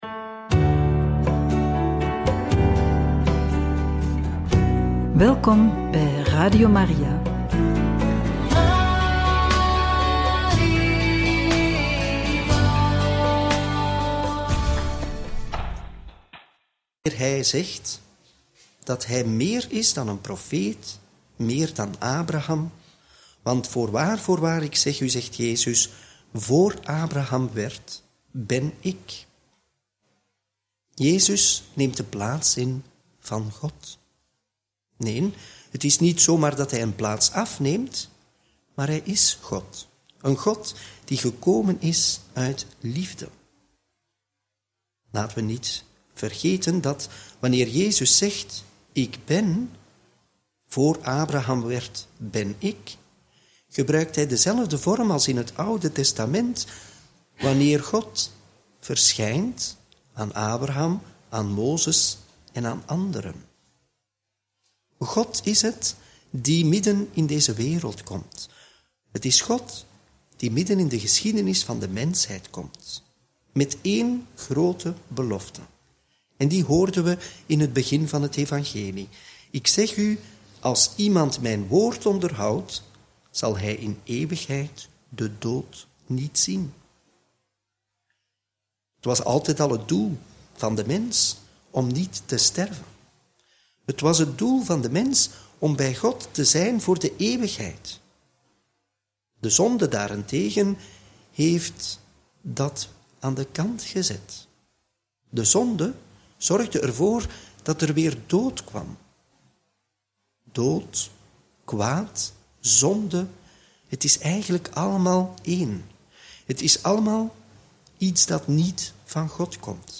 Homilie bij het Evangelie van donderdag 10 april 2025 – Joh. 8, 51-59